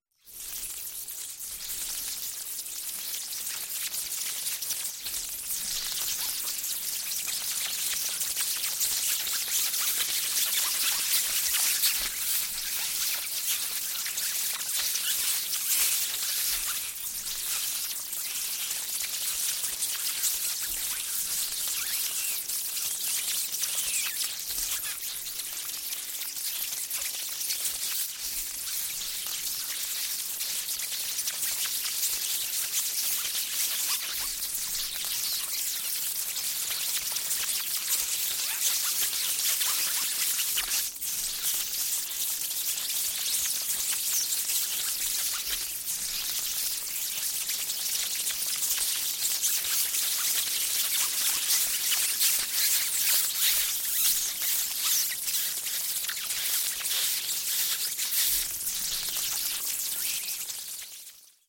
Звуки летучей мыши
Летучие мыши роем